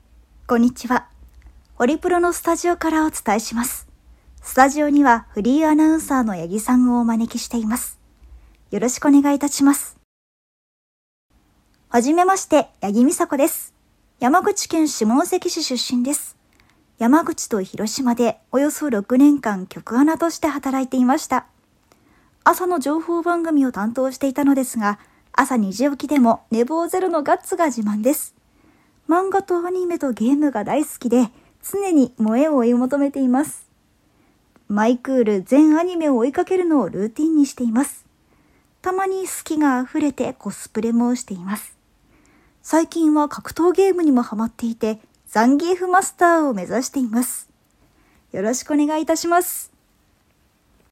ボイスサンプル
【自己紹介】